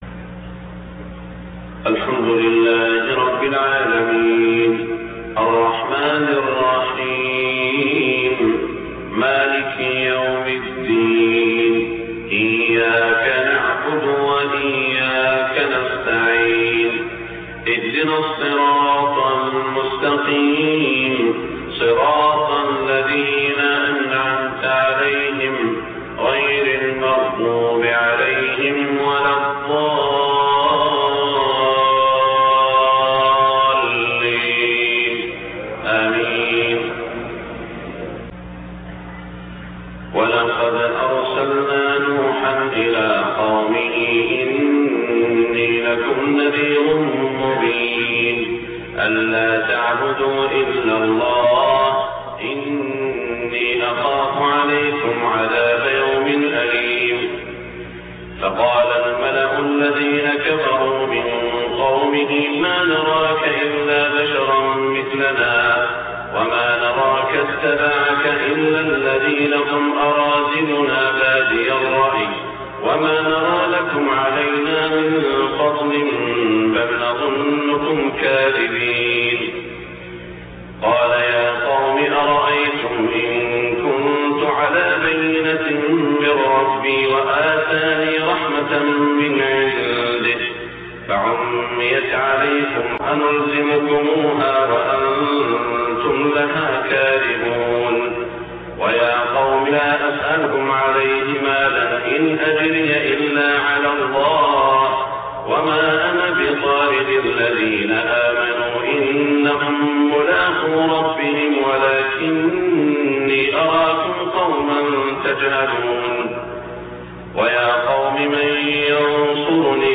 صلاة الفجر 8-4-1425 من سورة هود > 1425 🕋 > الفروض - تلاوات الحرمين